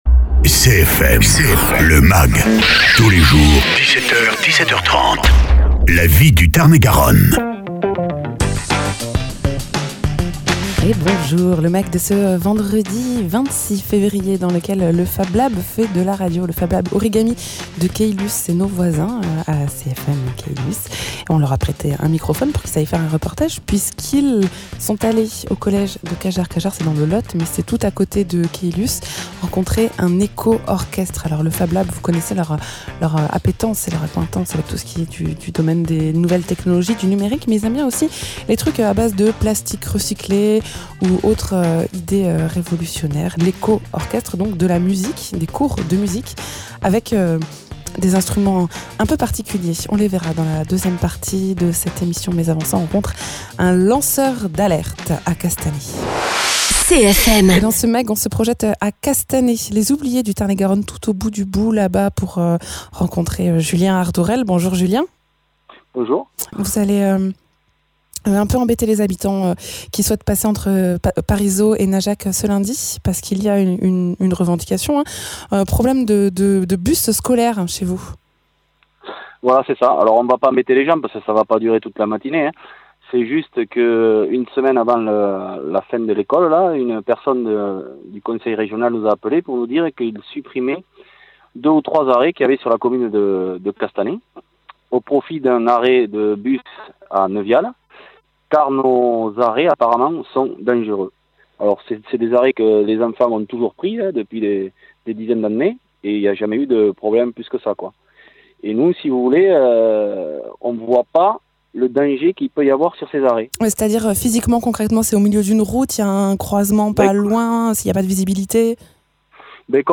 Opération route bloquée à Castanet pour protester contre la suppression un arrêt de bus scolaire, Reportage au collège de Cajarc pour la mise en place d’un éco-orchestre, grâce au Fablab Origami de Caylus